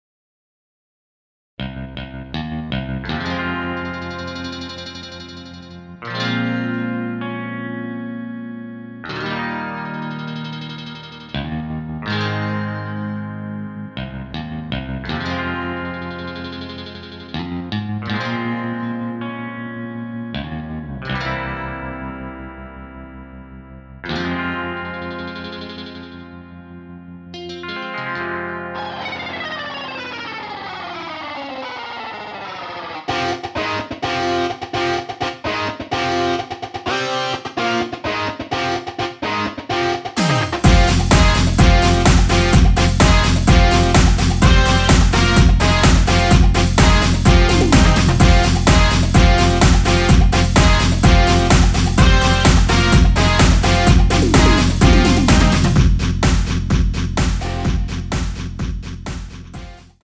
원키에서(-2)내린 MR입니다.
Fm
앞부분30초, 뒷부분30초씩 편집해서 올려 드리고 있습니다.
중간에 음이 끈어지고 다시 나오는 이유는